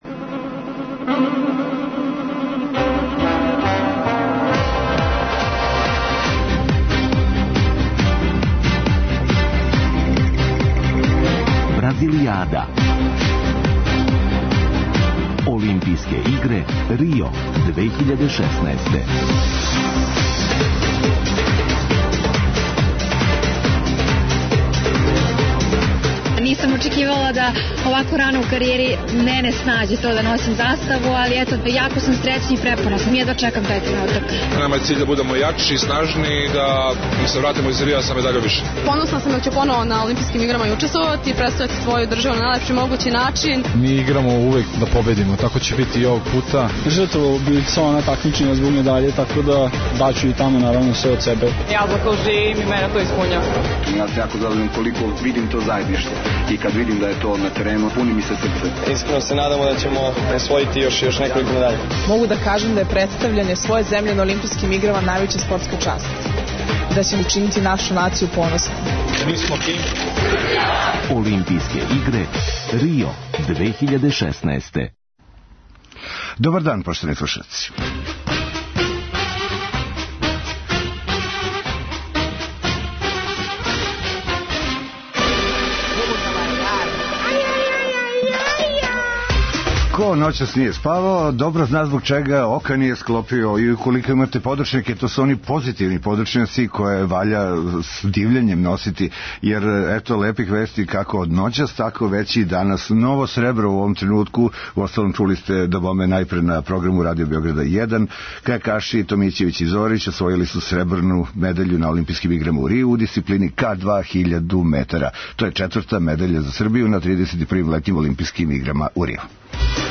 Специјална емисија која ће се емитовати током трајања Олимпијских игара у Рију. Пратимо наше спортисте који учествују на ОИ, анализирамо мечеве, уз госте у Студију 1 Радио Београда и укључења наших репортера са лица места.